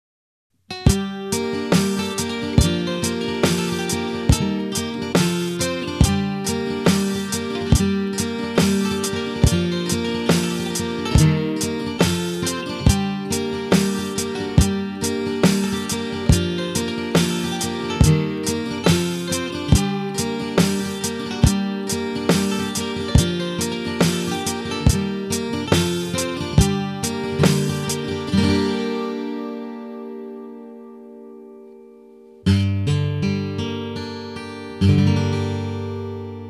ただ・・σ(￣∇￣;)アルペジオ苦手で・・・指弾きできないんっわ。
サスティン効果や響きが分からなくなる為弦は死んでます　＜変えるのが面倒なだけ（爆）
Martinが左チャンネル、S.Yairiが右チャンネルとなっております。
音響効果（エコー、リバーブ等）は使っておりません。